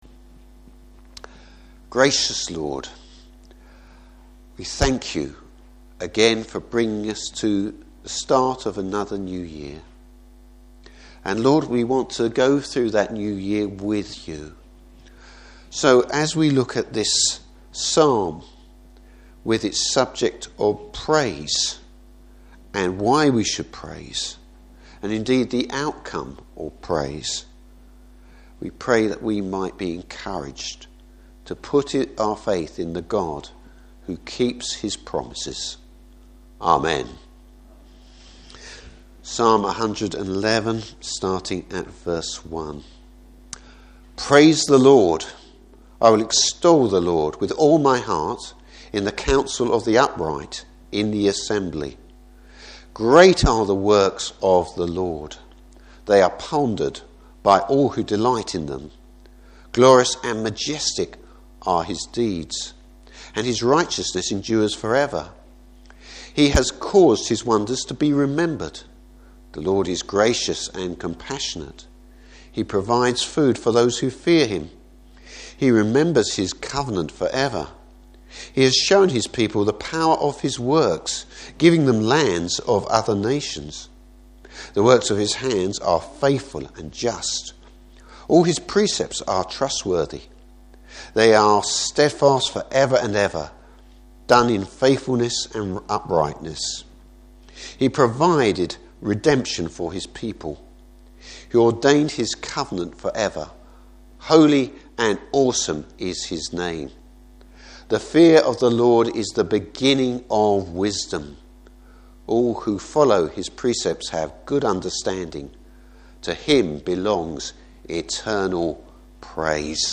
Service Type: Morning Service How real wisdom is found by fearing God and praise!